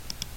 鼠标点击一次
描述：单击鼠标
Tag: 鼠标 鼠标 计算机